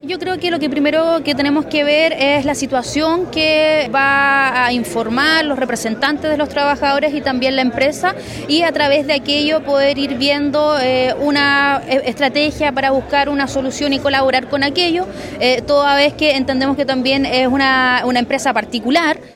En ese sentido, la delegada Presidencial de Los Lagos, Paulina Muñoz, señaló que “lo primero que tenemos que ver es la situación que va a informar los representantes de los trabajadore y también la empresa”.